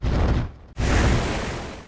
Smokescreen.mp3